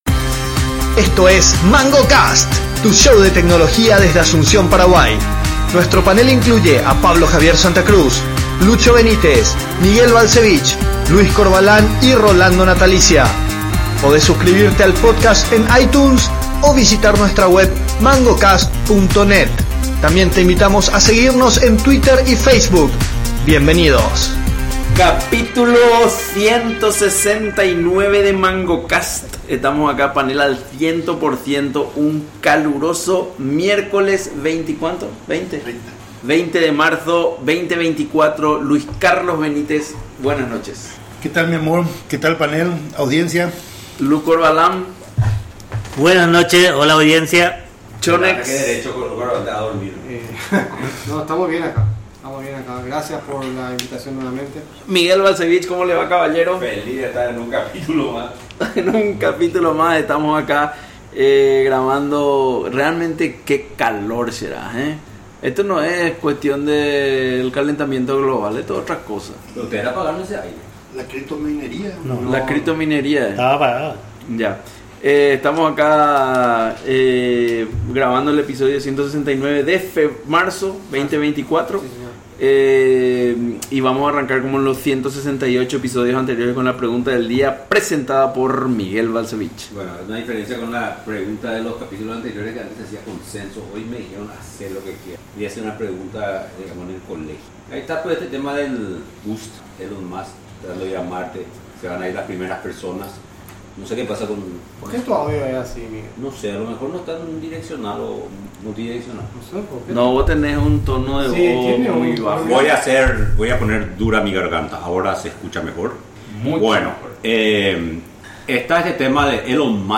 Los panelistas se sumergen en una dinámica discusión, explorando diferentes perspectivas y respuestas a la pregunta.